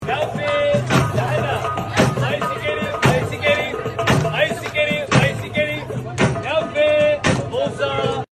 Ai Scream Sound Button - Free Download & Play